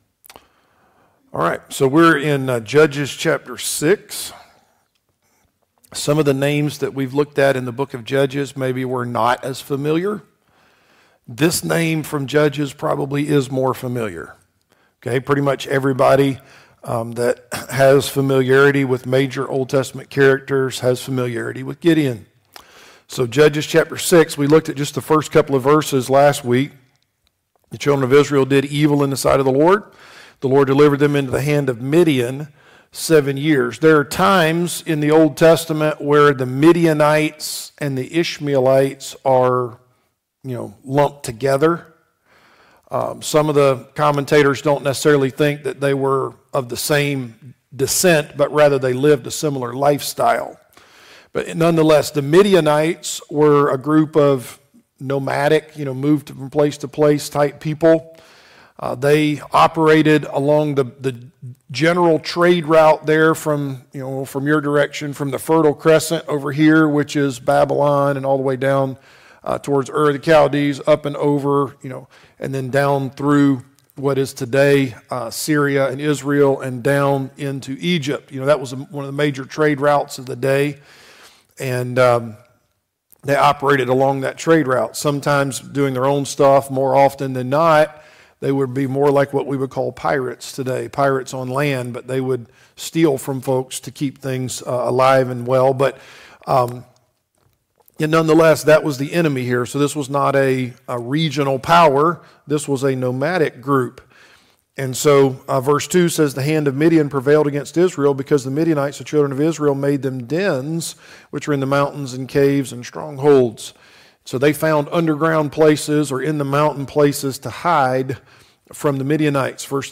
Judges Passage: Judges 6 Service Type: Adult Sunday School Class « Better Than We Deserve What Causes People to “Crash & Burn” »